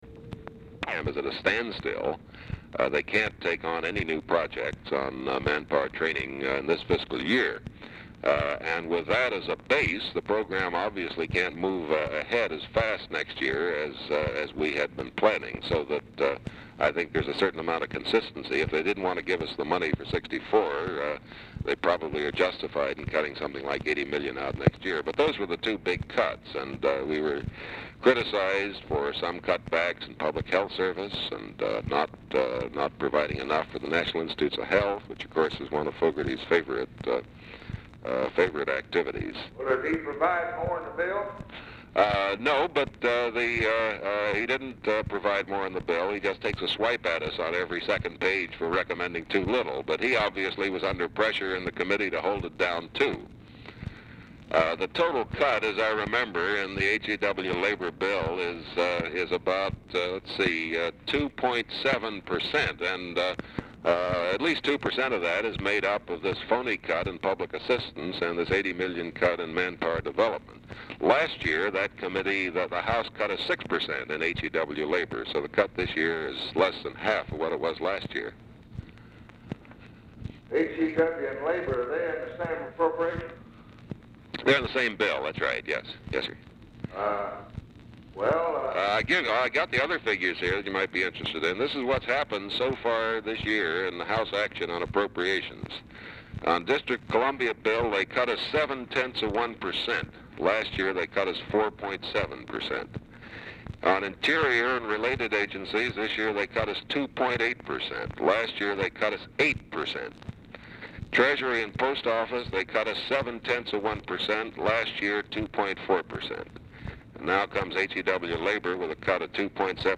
Telephone conversation # 3007, sound recording, LBJ and KERMIT GORDON, 4/11/1964, 11:55AM
LBJ ON SPEAKERPHONE?
Format Dictation belt